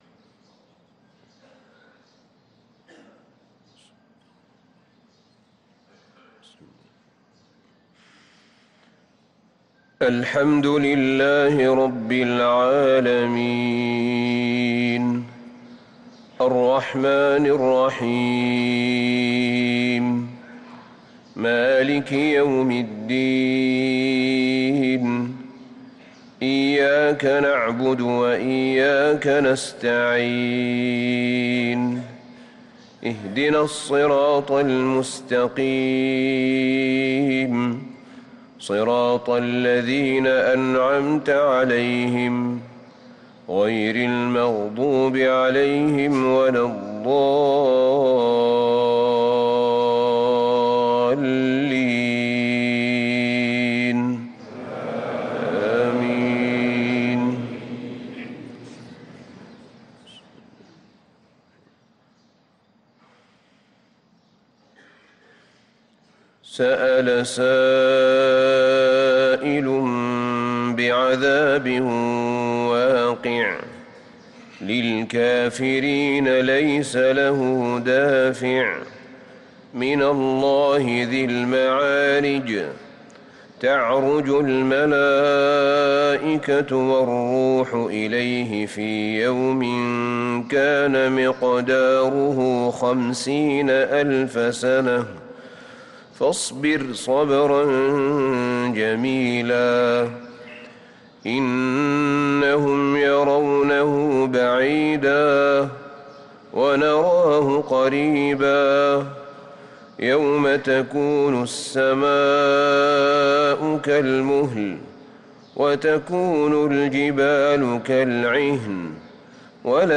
صلاة الفجر للقارئ أحمد بن طالب حميد 28 شعبان 1444 هـ
تِلَاوَات الْحَرَمَيْن .